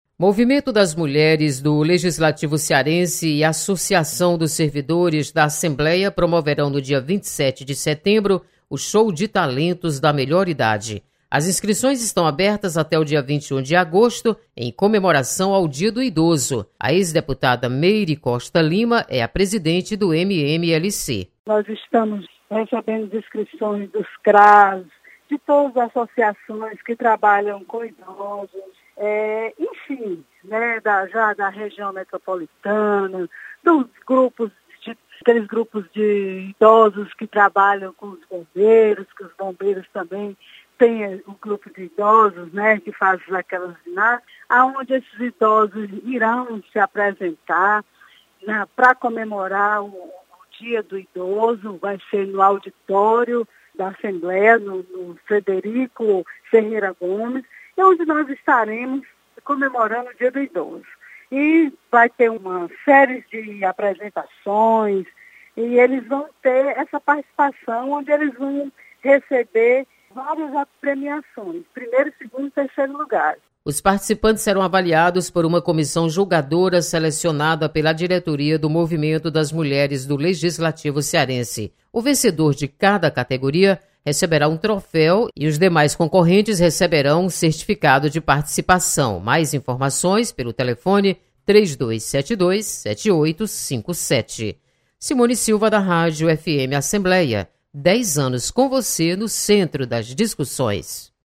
Movimento das Mulheres do Legislativo Cearense promove ação no dia do Idoso. Repórter